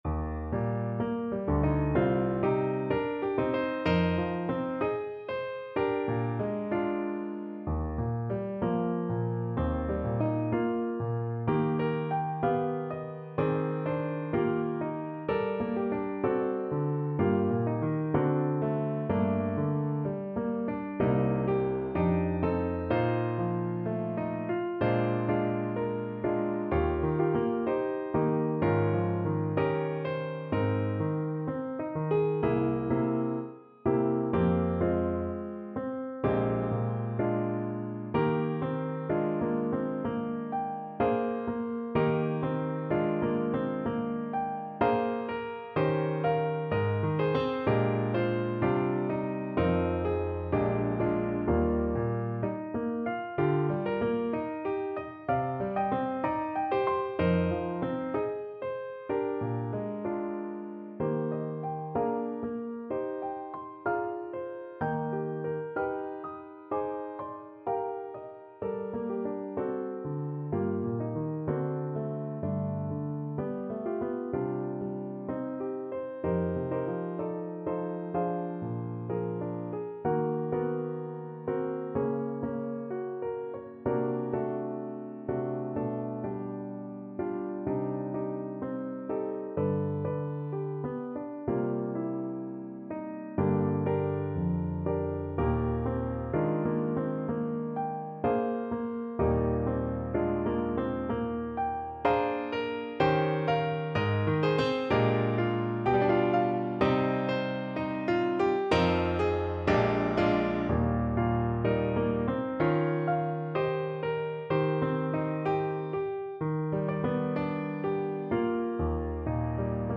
Classical (View more Classical Voice Music)